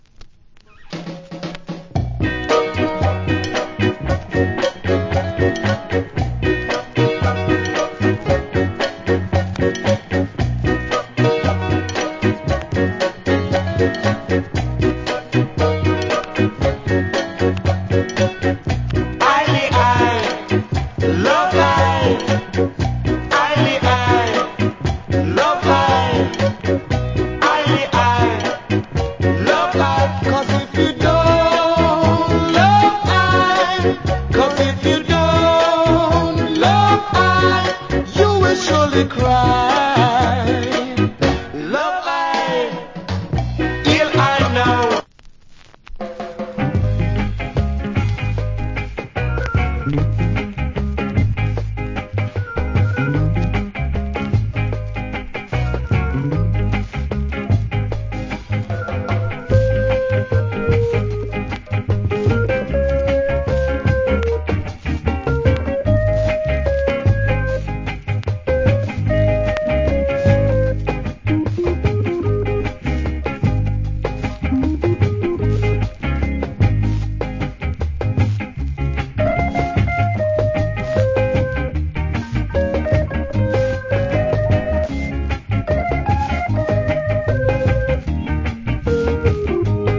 Good Early Reggae Vocal.